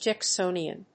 音節Jack・so・ni・an 発音記号・読み方
/dʒæksóʊniən(米国英語), dʒæˈksəʊni:ʌn(英国英語)/